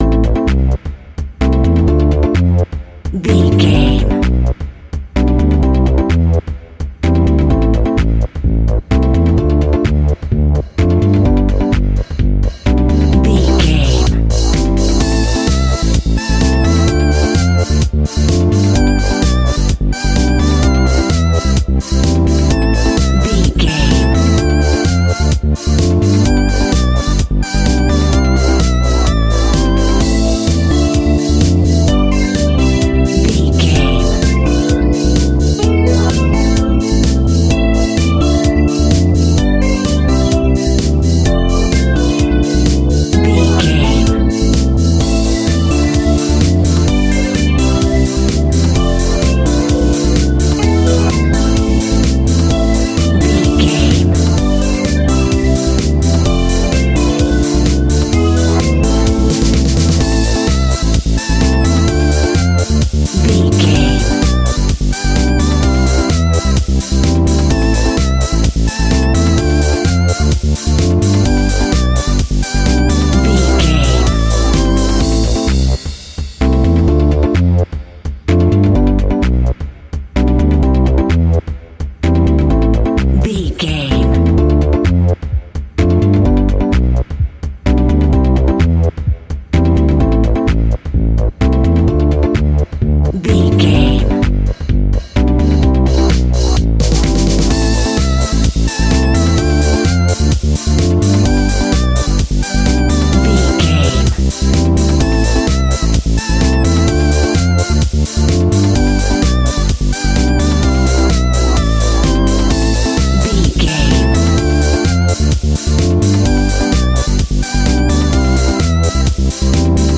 Ionian/Major
groovy
uplifting
futuristic
drums
synthesiser
upbeat
instrumentals
funky guitar
wah clavinet
synth bass
horns